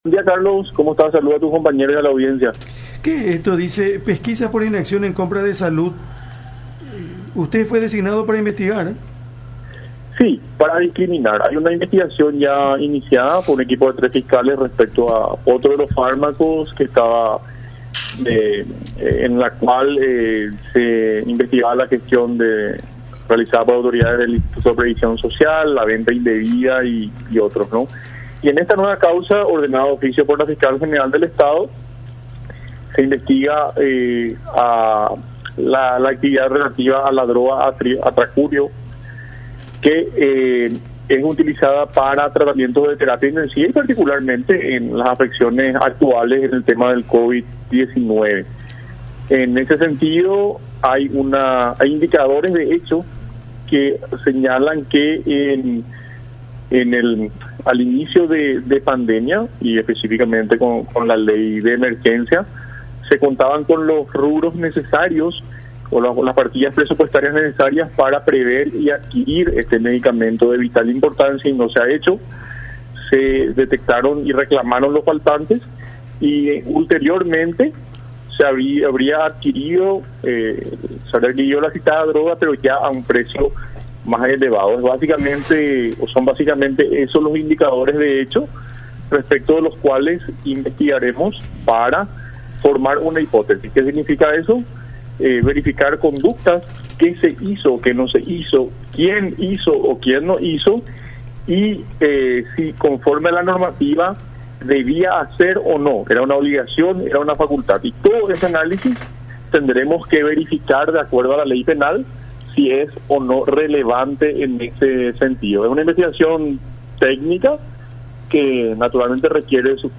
“Se detectaron y reclamaron los faltantes, por lo que investigaremos para establecer las hipótesis. Estamos en el día 1 de la investigación, y la responsabilidad será de todas y cada una de las personas que participaron, sea del rango que fuera. Vamos a verificar conductas, qué se hizo, qué no se hizo, quiénes hicieron y si conforme a la normativa debían hacerlo”, dijo Pecci en diálogo con La Unión.